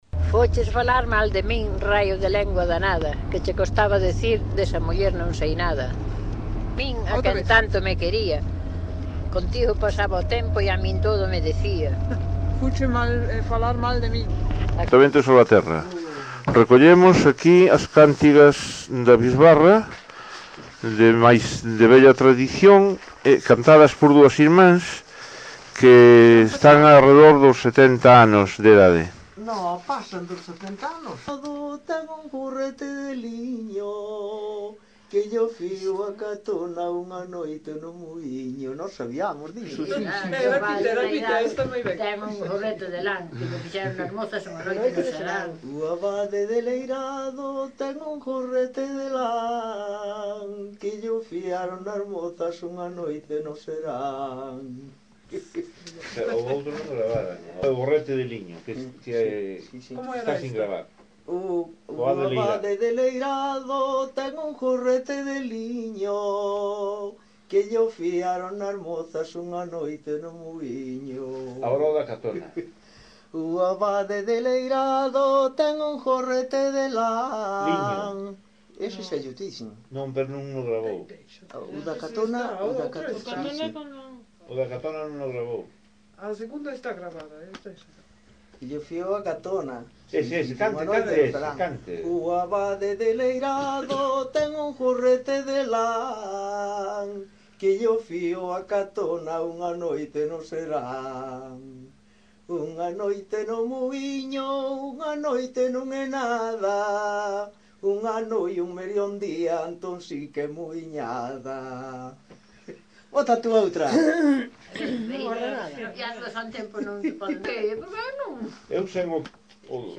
Notas da recolla
1980 Concello: Salvaterra de Miño.